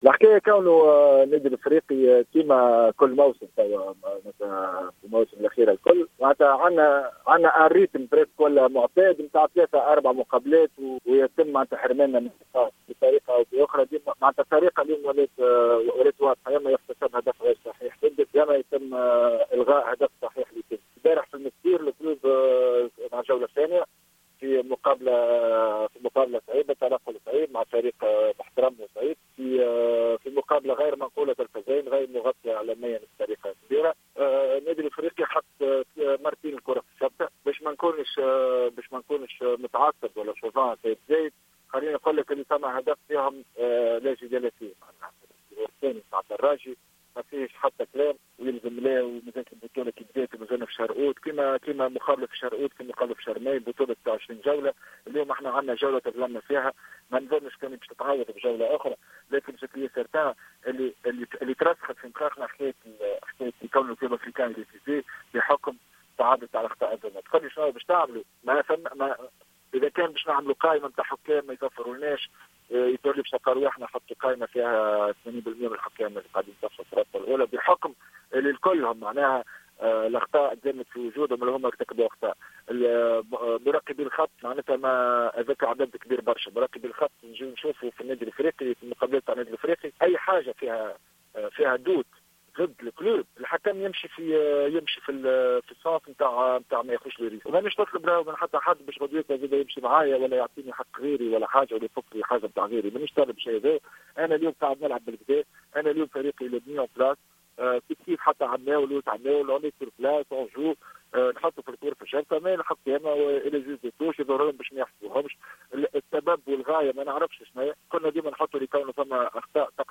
في إتصال هاتفي للجوهرة افم